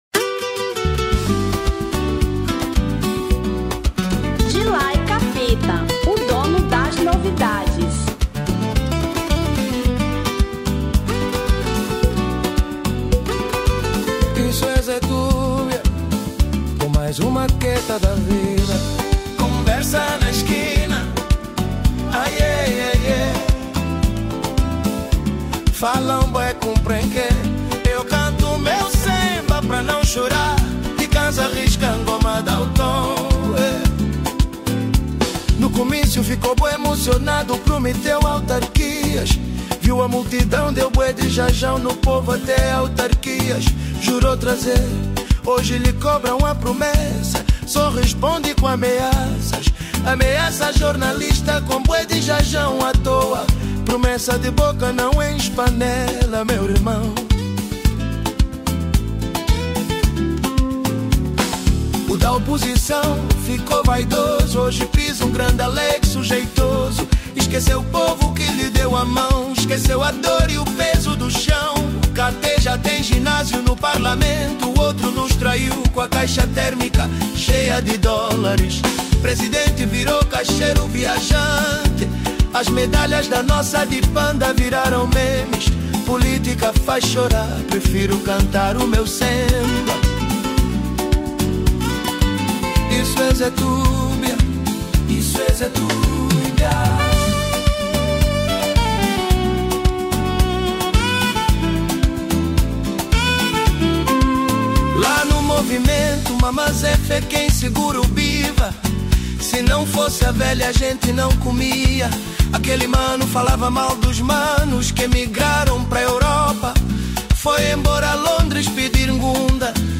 Semba 2025